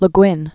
(lə gwĭn), Ursula K(roeber) 1929-2018.